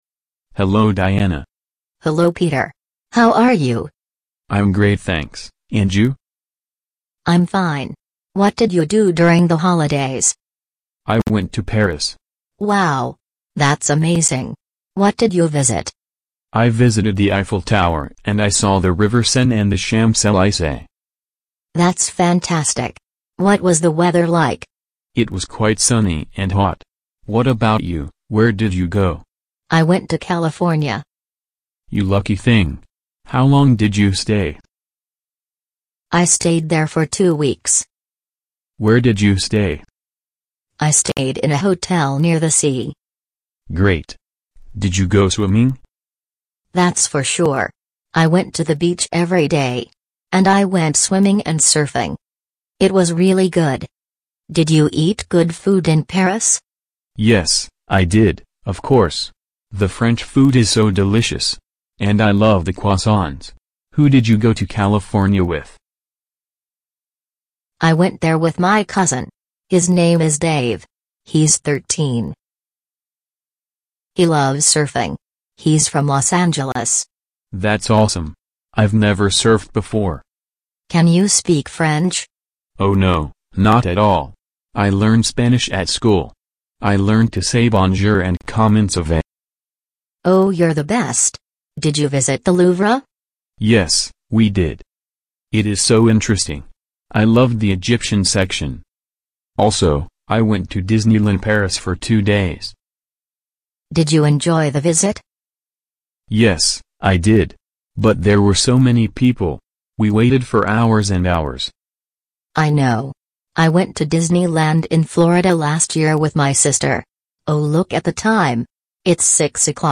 dialogue-holidays.mp3